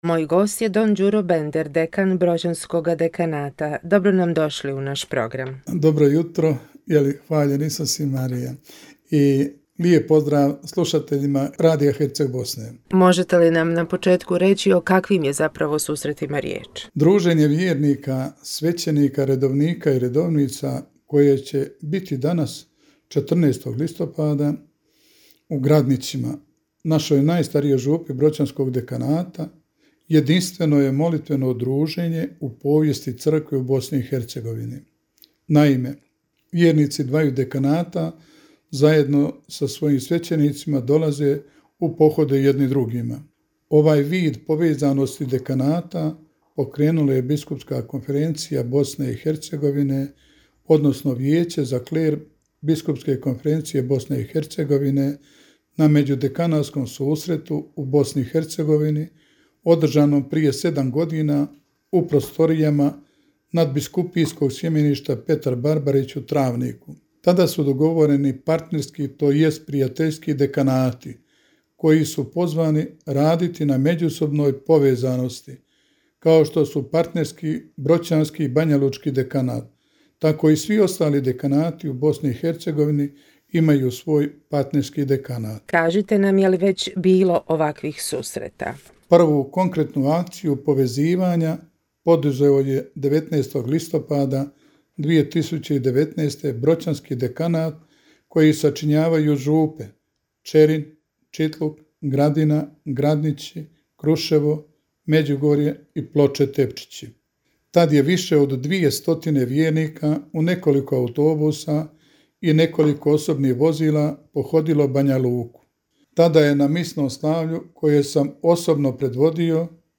U današnjem jutarnjem radijskom programu Radio-televizije Herceg Bosne predstavljeno je dugogodišnje prijateljstvo dva crkvena Dekanata – Banjalučkog i Broćanskog.